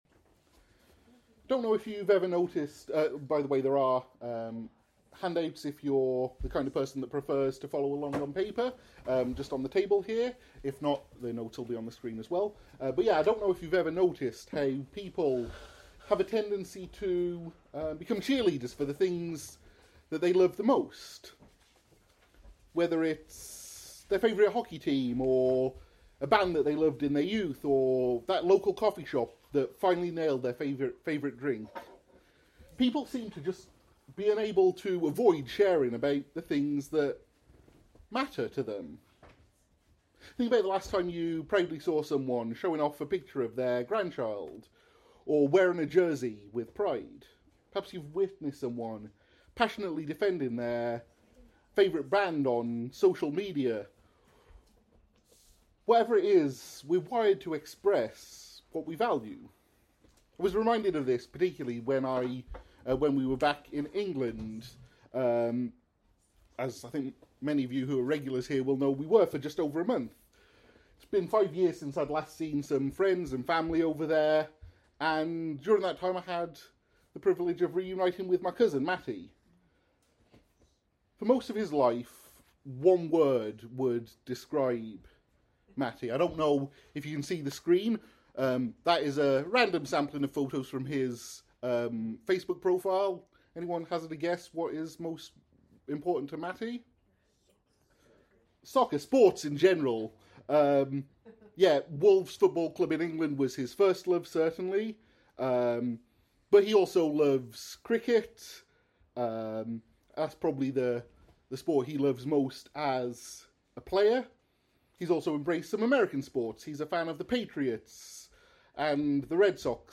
In this sermon, we walk through five key truths: 1. A Sent People — God’s mission began long before us. 2.